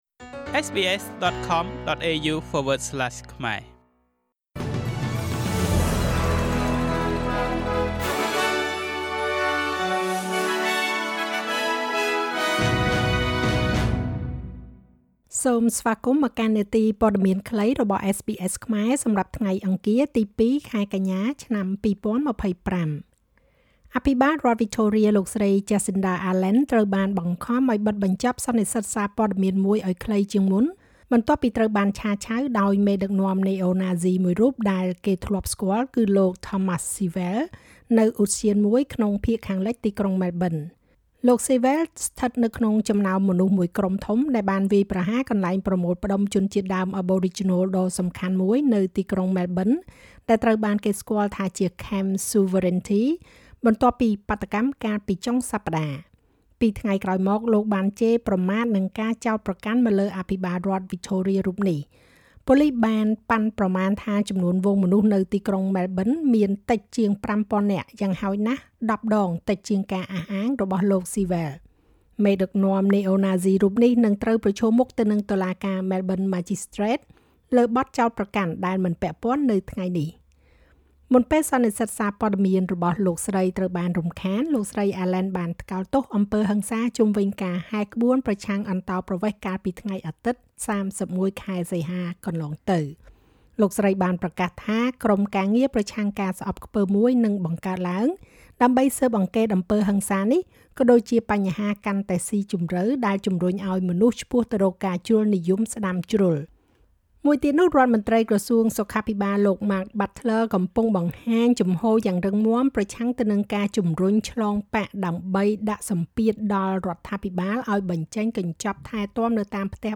នាទីព័ត៌មានខ្លីរបស់SBSខ្មែរ សម្រាប់ថ្ងៃអង្គារ ទី២ ខែកញ្ញា ឆ្នាំ២០២៥